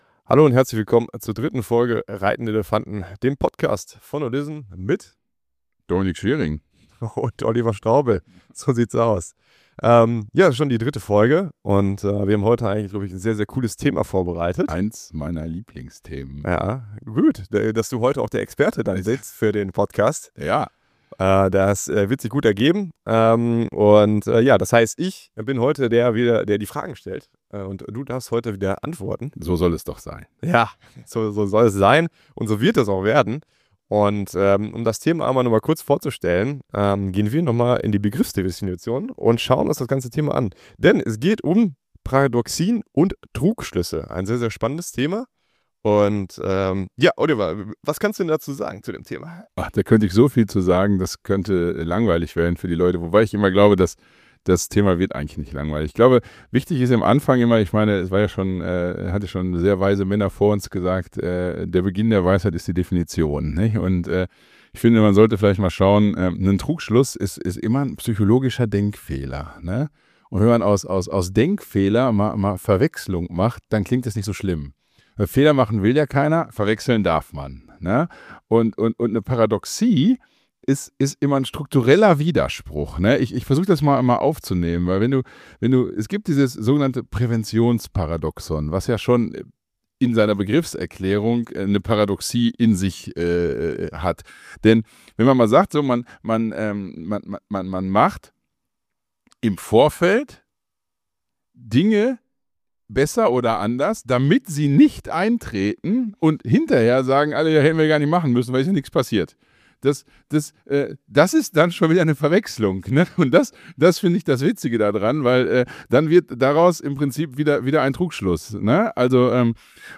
unsere Stimme aus dem Off Worum geht’s?